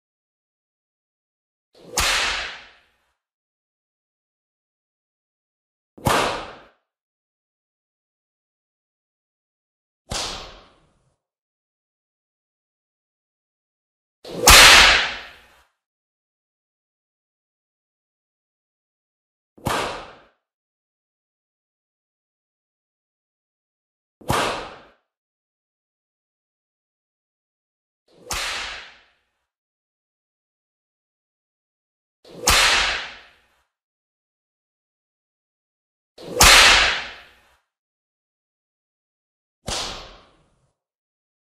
دانلود صدای شلاق 2 از ساعد نیوز با لینک مستقیم و کیفیت بالا
جلوه های صوتی